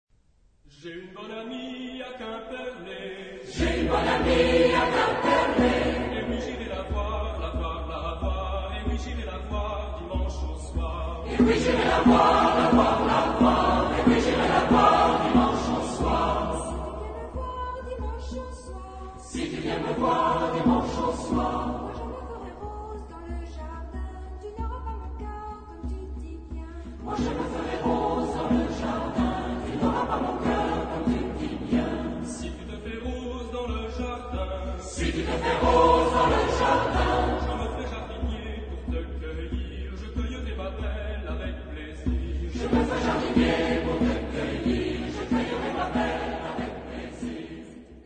Genre-Style-Forme : Profane ; Folklore
Caractère de la pièce : affectueux
Type de choeur : SATB  (4 voix mixtes )
Solistes : Mezzosoprano (1)  (1 soliste(s))
Tonalité : ré majeur